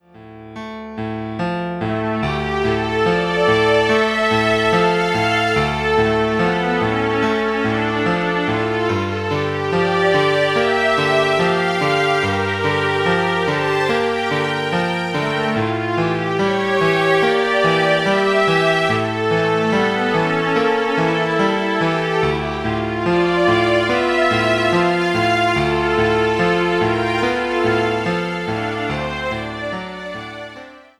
violin ens. & piano